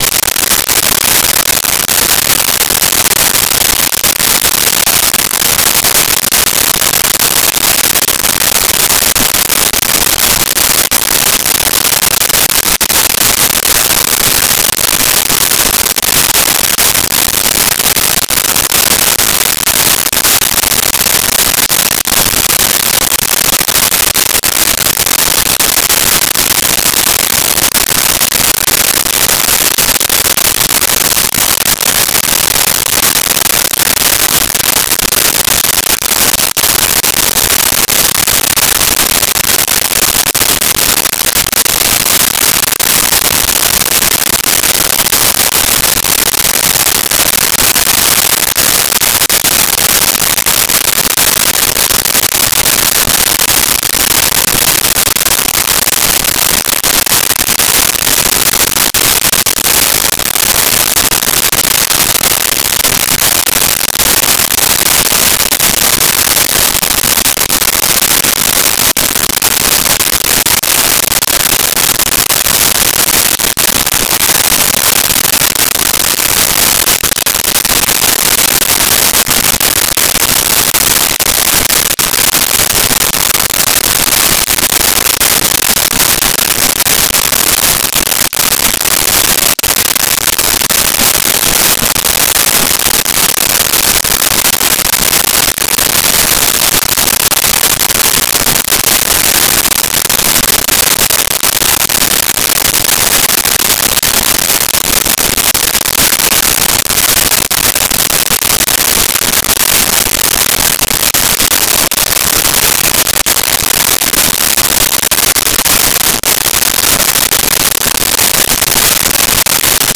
Rain Drops From Roof
Rain Drops From Roof.wav